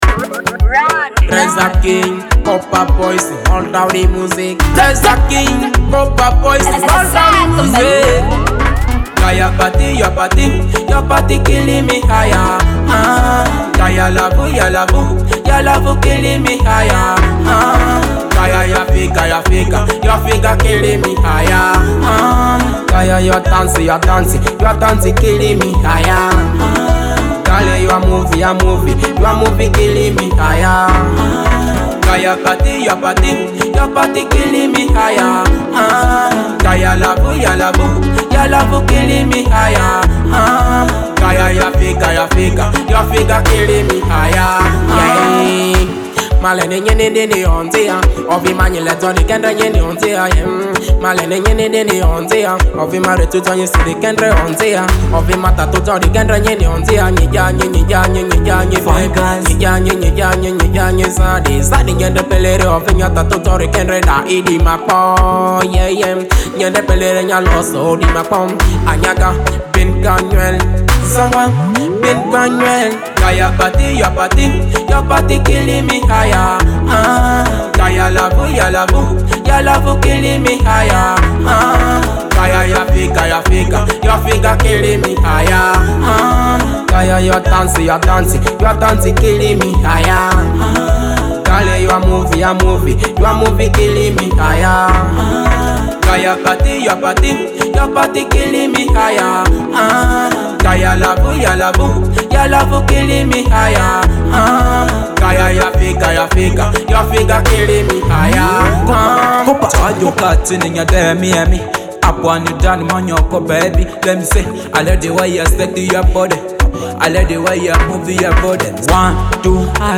Genre: Afro